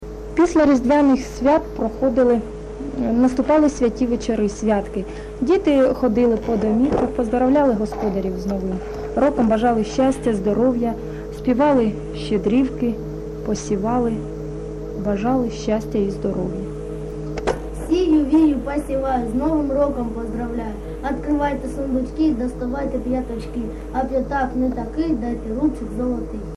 ЖанрПосівальні
Місце записус. Келеберда, Кременчуцький район, Полтавська обл., Україна, Полтавщина